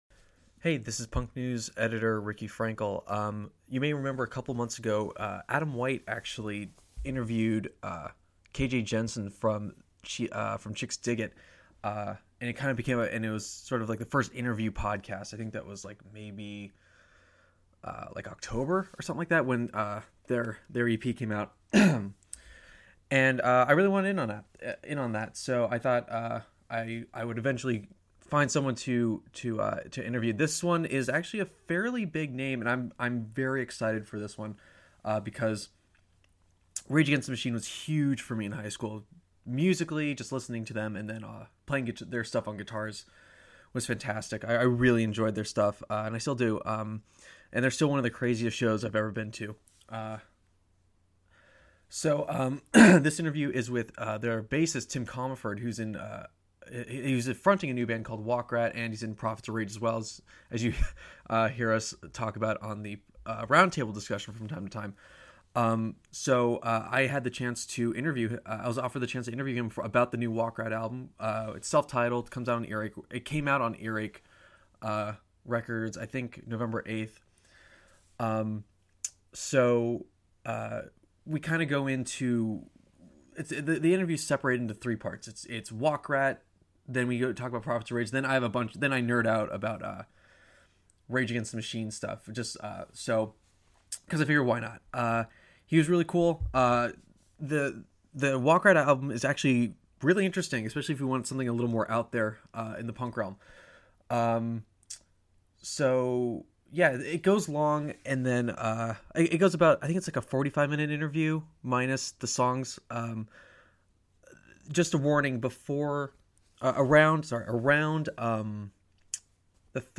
Interview With Tim Commerford (Wakrat/Rage Against The Machine)